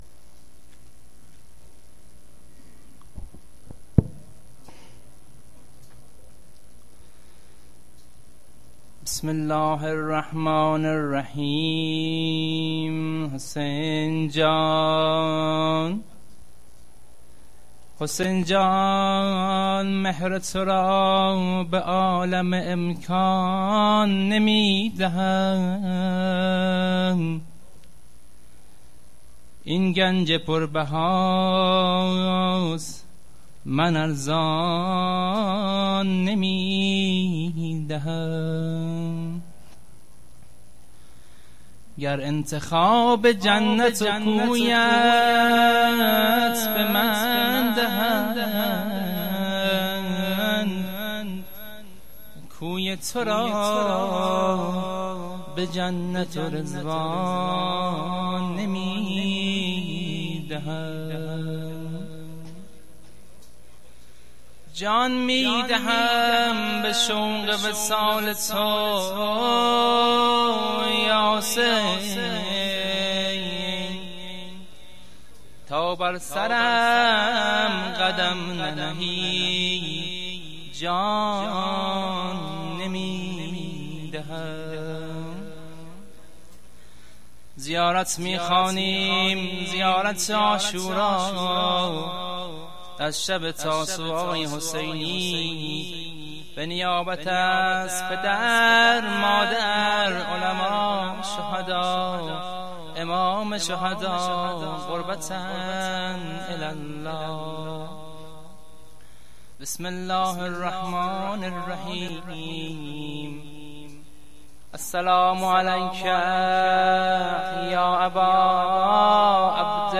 خیمه گاه - هیئت مهدیه احمد آباد - زیارت عاشورا-شب نهم محرم97-مهدیه احمدآباد